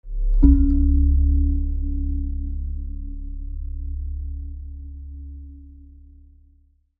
UI_SFX_Pack_61_60.wav